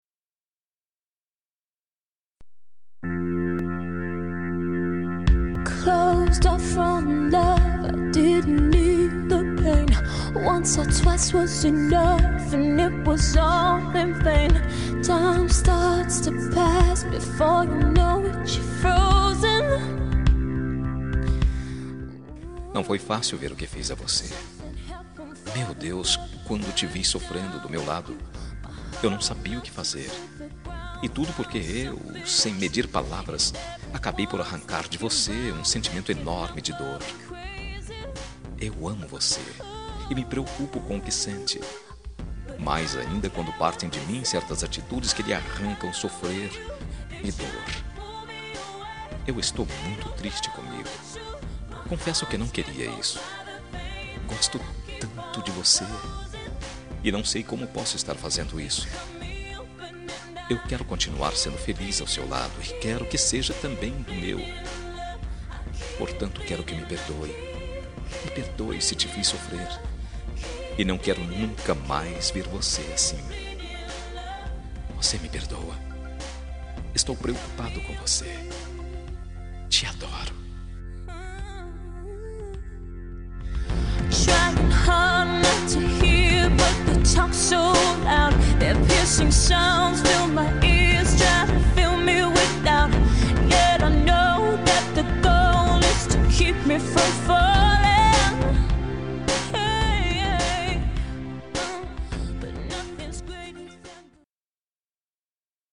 Telemensagem de Desculpas – Voz Masculina – Cód: 201814